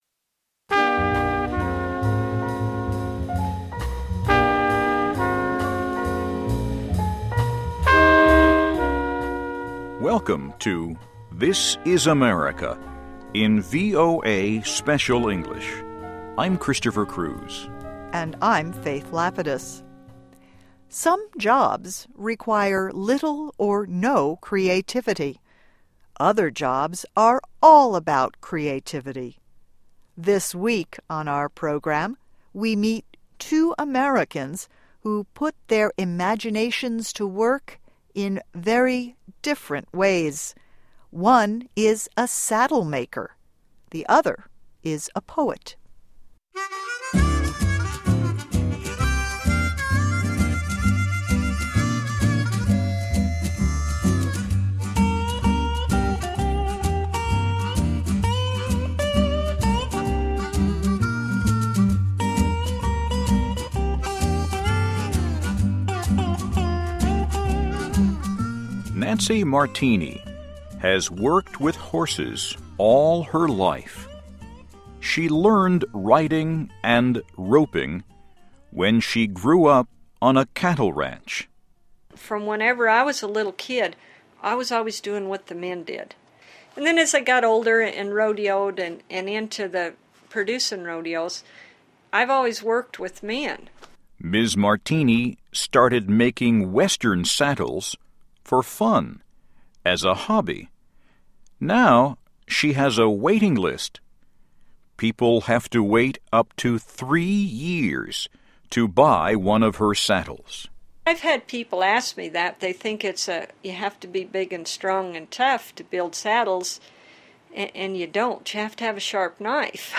A Saddle Maker and a Poet: How They Put Their Creativity to Work (VOA Special English 2011-01-30)
Listen and Read Along - Text with Audio - For ESL Students - For Learning English